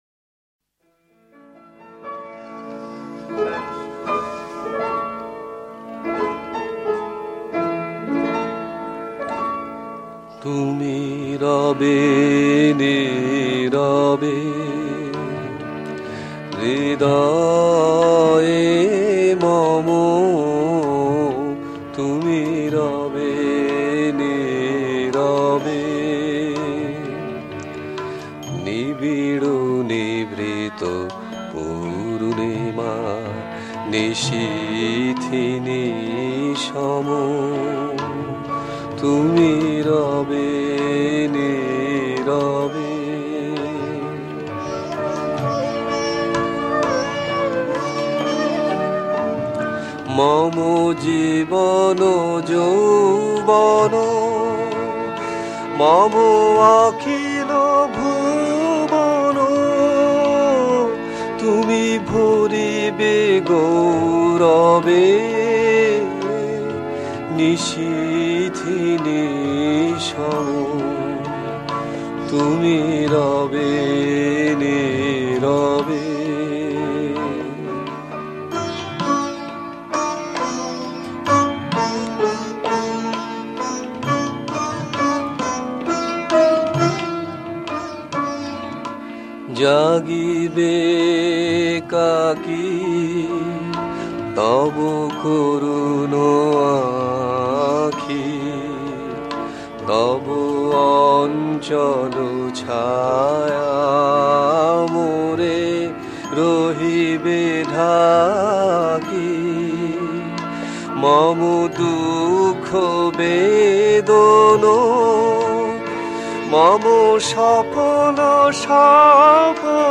Genre Rabindra Sangeet